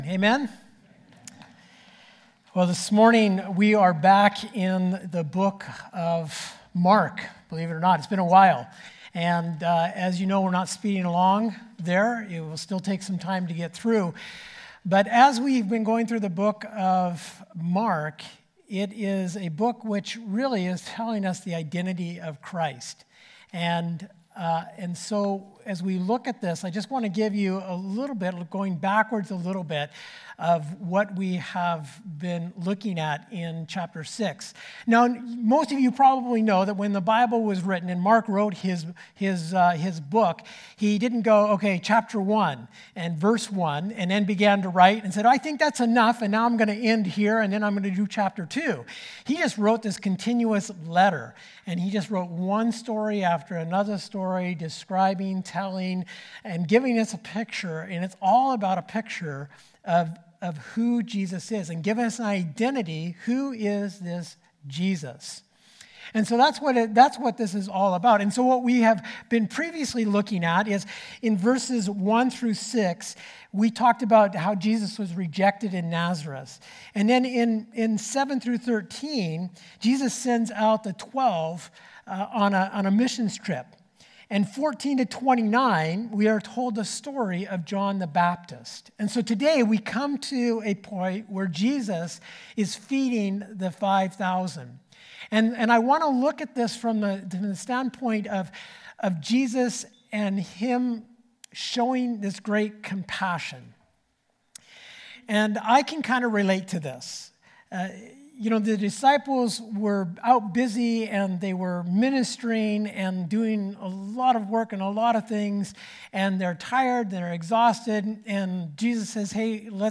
Sermons | Oceanview Community Church